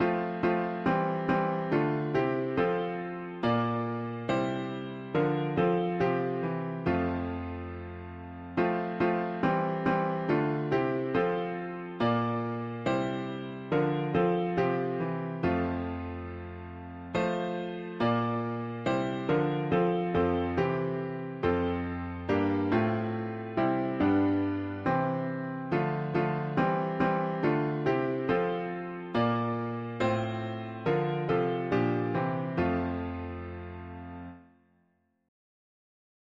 Sons and daughters of the Lord, serving one anothe… english christian 4part chords
Key: G major Meter: 76.76 D